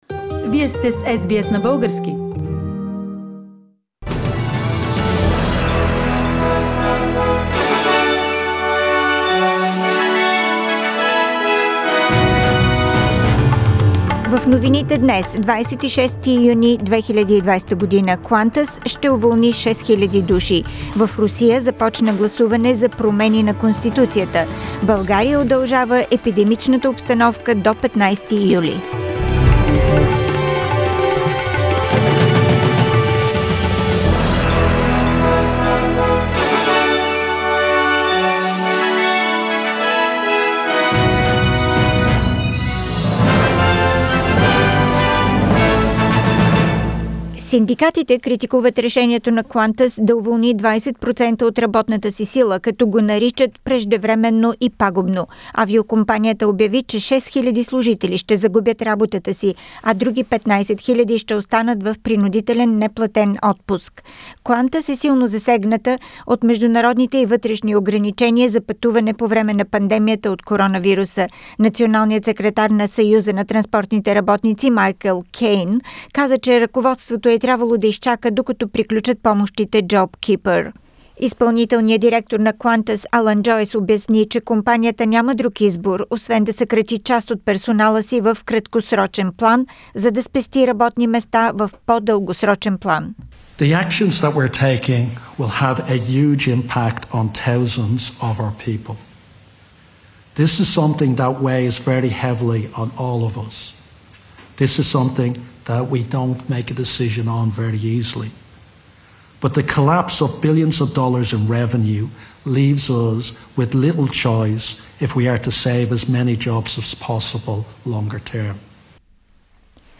Weekly Bulgarian News - 26th June 2020